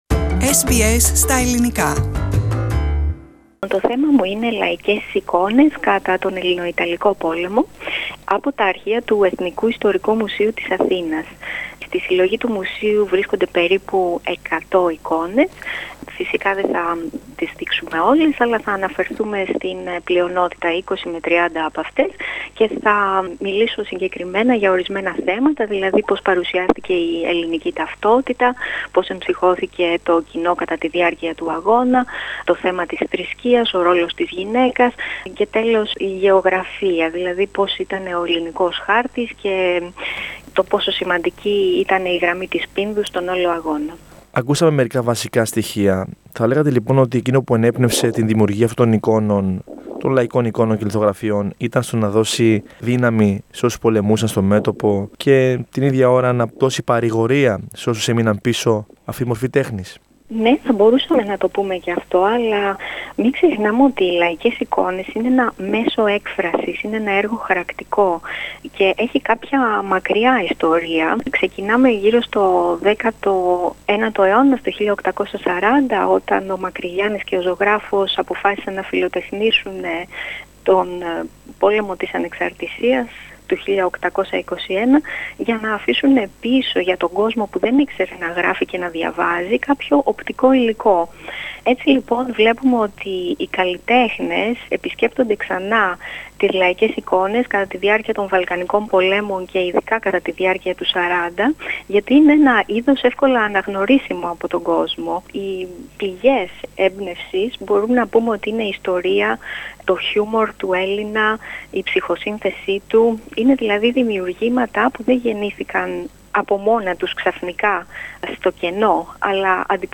Περισσότερα στη συνέντευξη